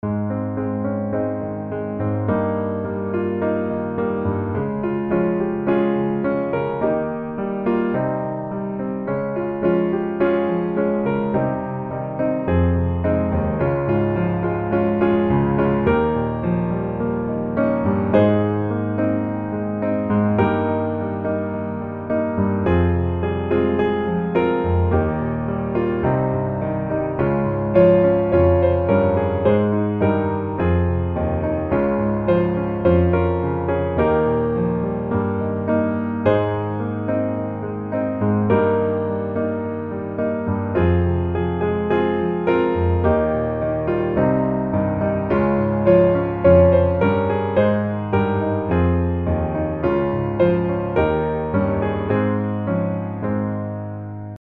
Ab Majeur